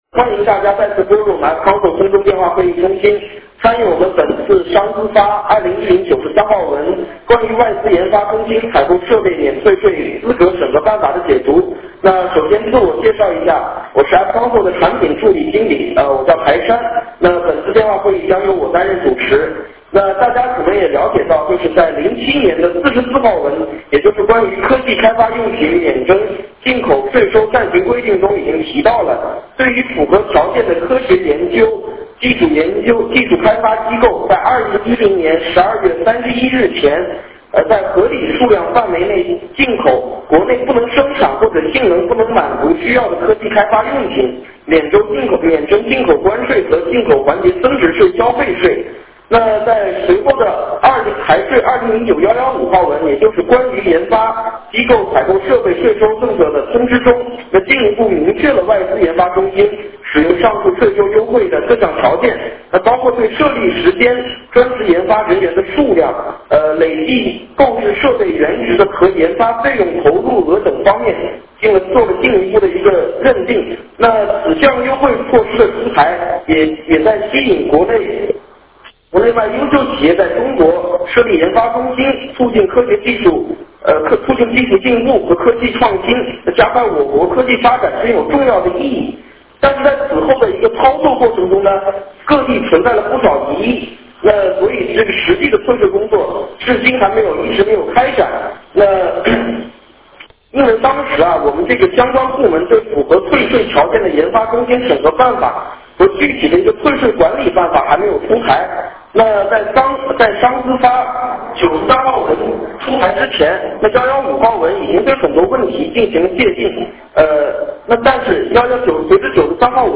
电话会议
王军 商务部外资司处长